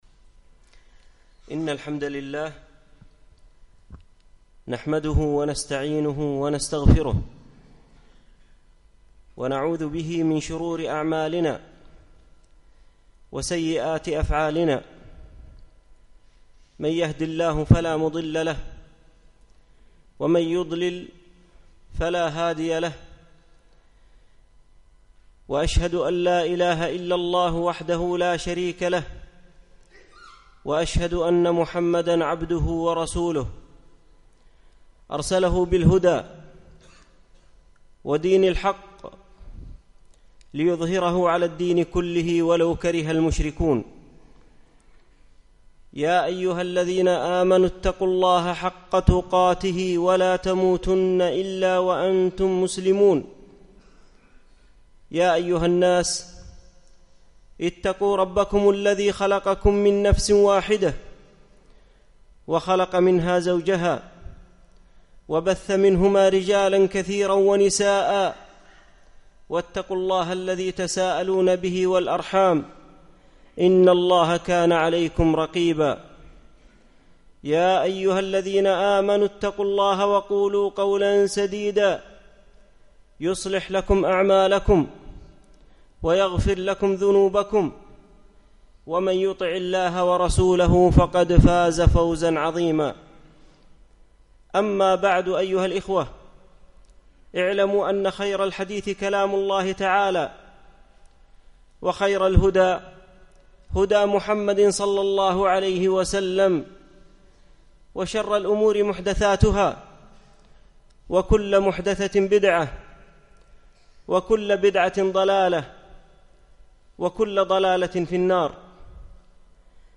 تحقيق التوحيد - خطبة